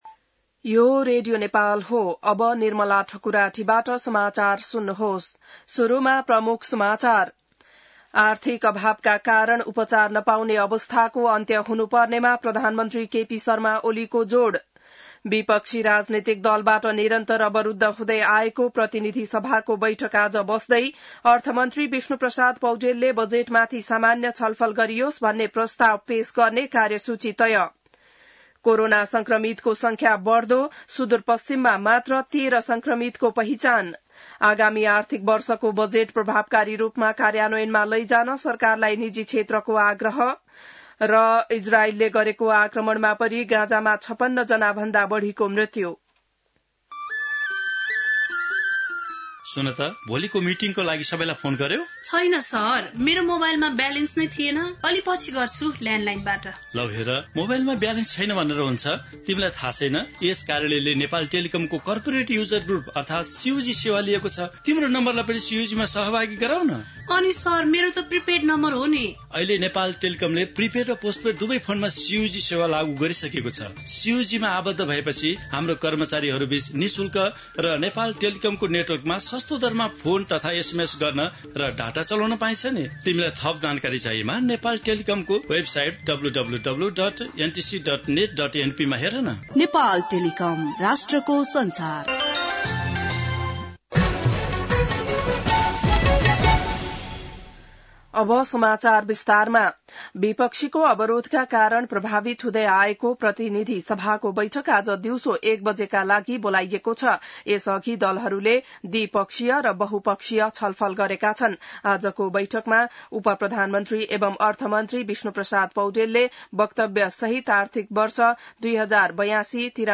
बिहान ७ बजेको नेपाली समाचार : २५ जेठ , २०८२